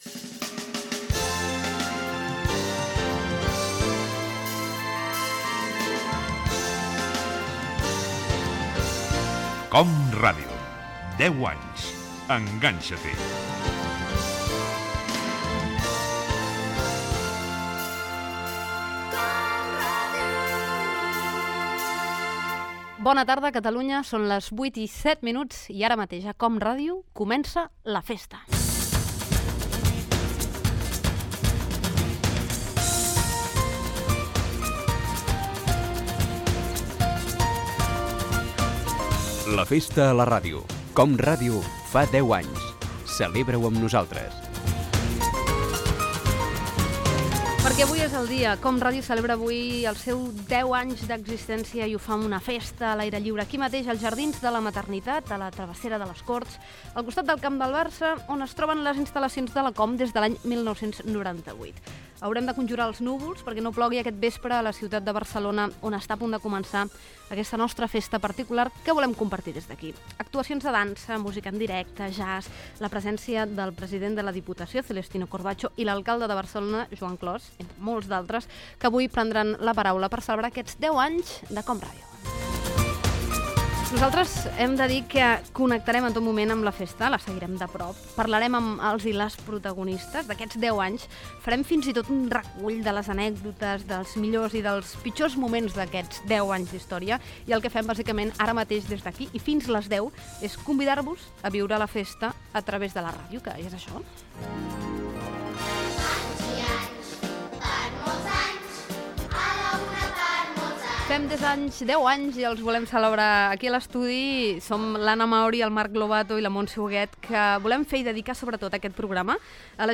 Entreteniment
Presentador/a
FM